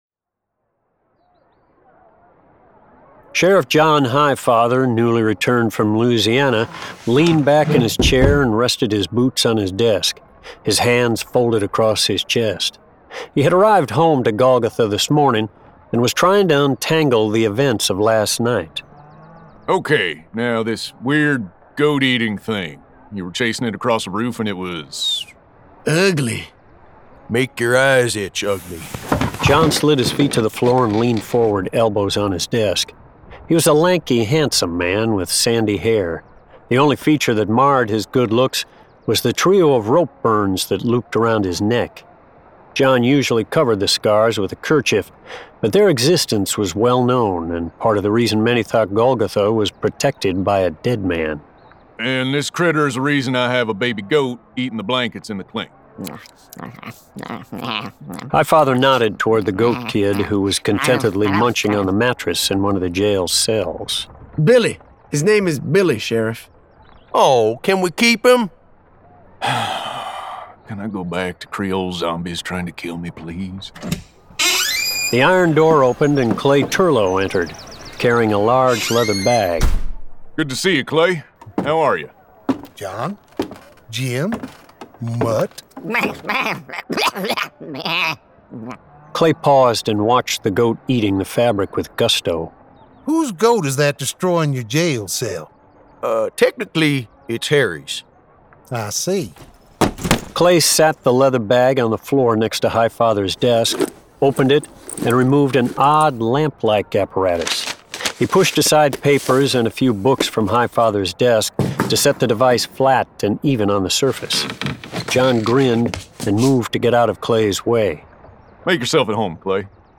Golgotha 2: The Shotgun Arcana 1 of 2 [Dramatized Adaptation]